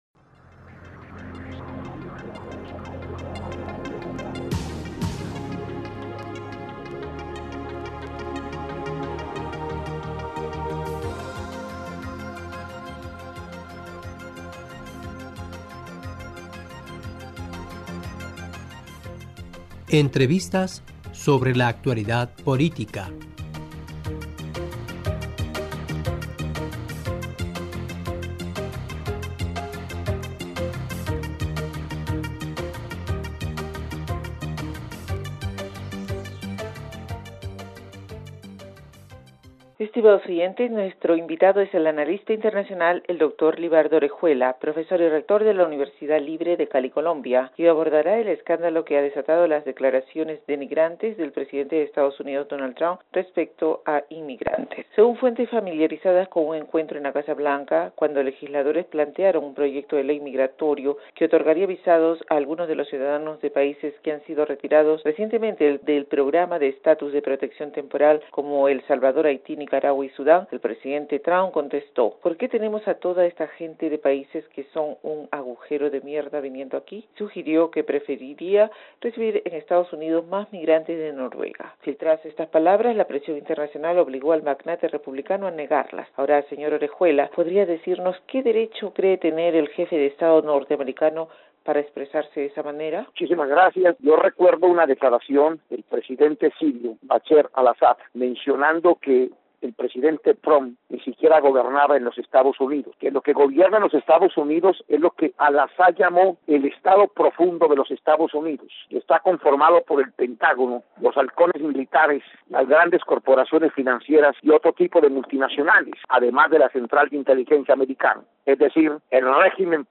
Estimados oyentes